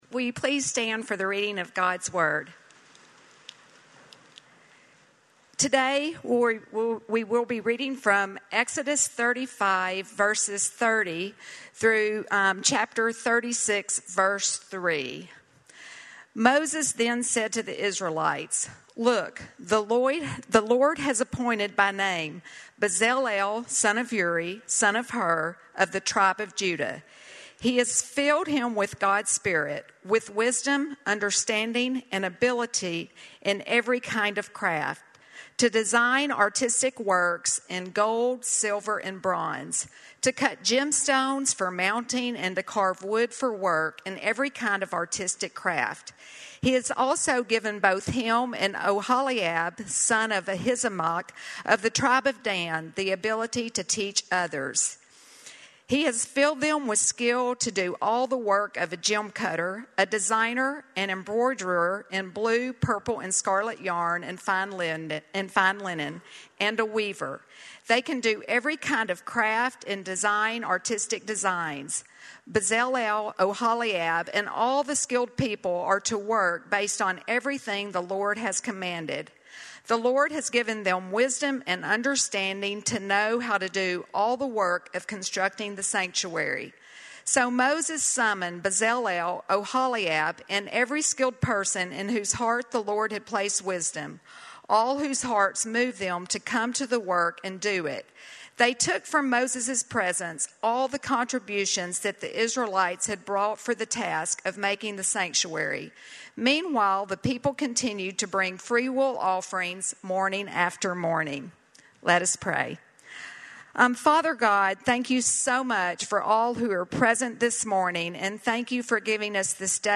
Called Out by Name - Sermon - Lockeland Springs